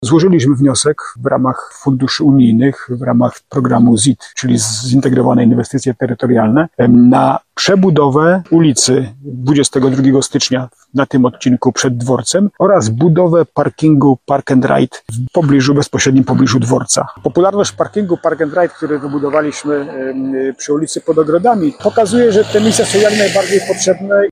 Takie miejsca są potrzebne – mówi Jacek Lelek, burmistrz Starego Sącza.